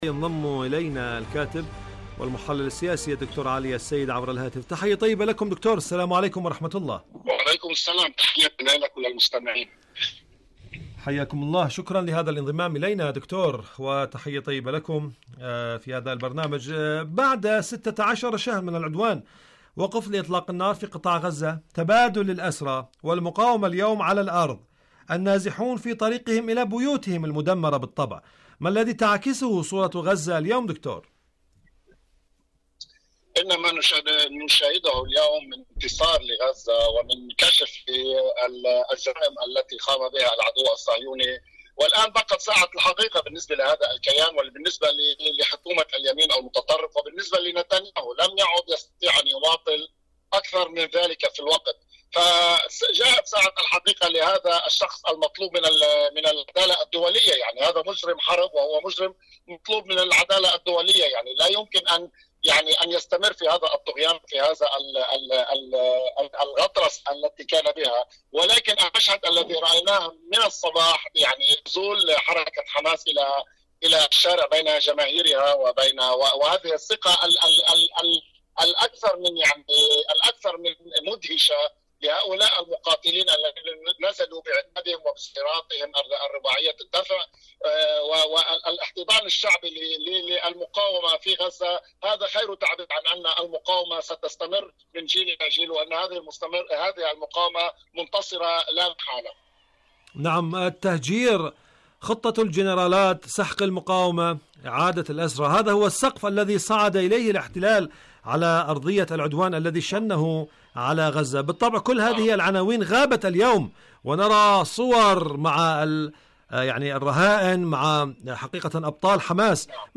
إذاعة طهران- فلسطين اليوم: مقابلة إذاعية